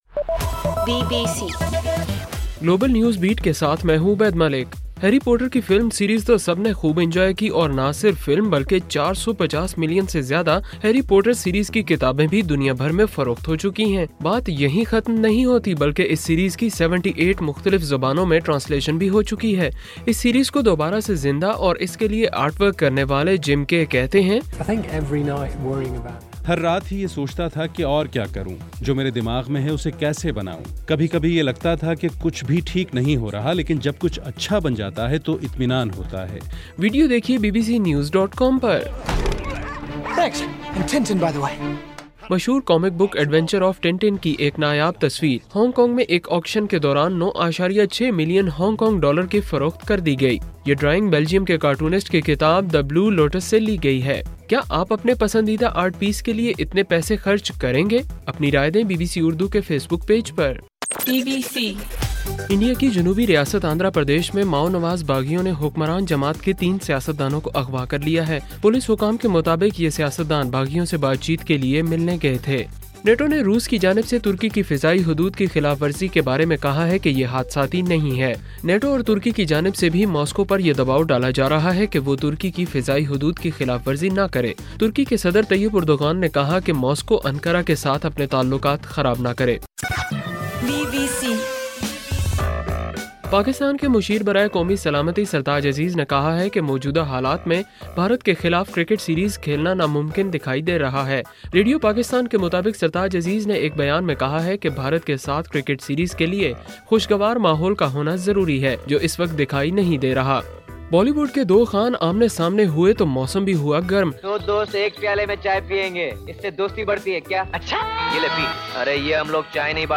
اکتوبر 7:صبح 1 بجے کا گلوبل نیوز بیٹ بُلیٹن